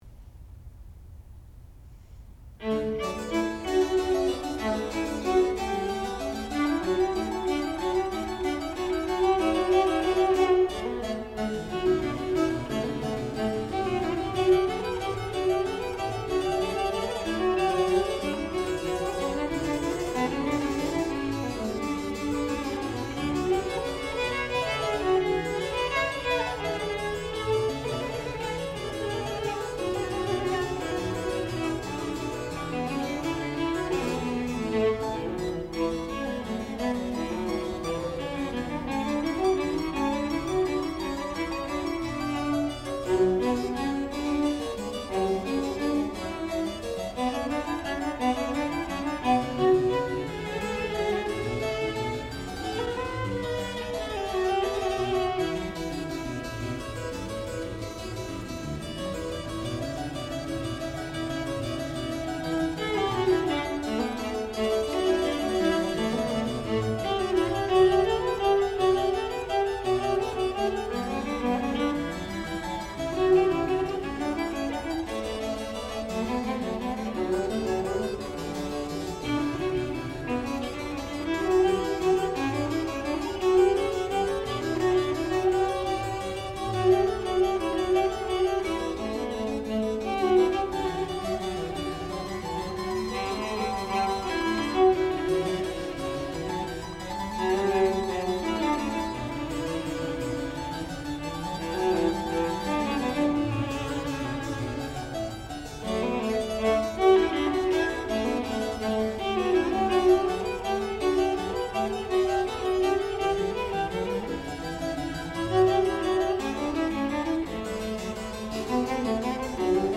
sound recording-musical
classical music
harpsichord
Master's Recital
viola